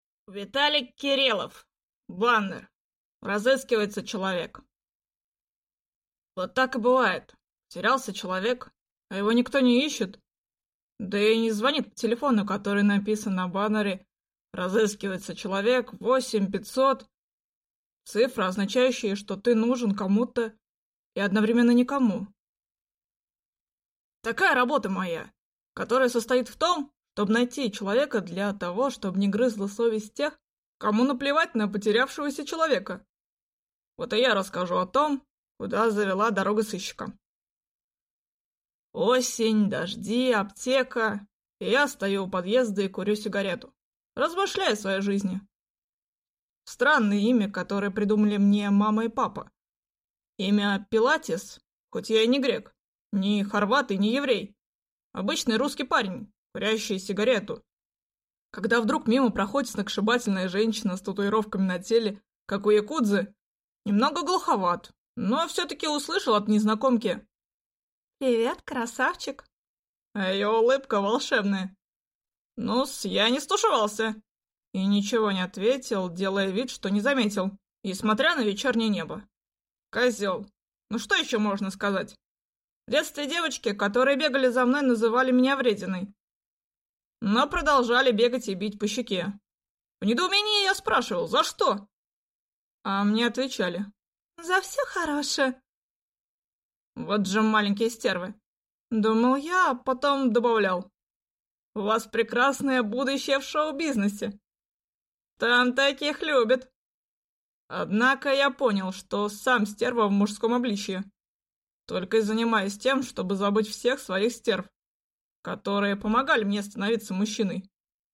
Aудиокнига Баннер «Разыскивается человек!»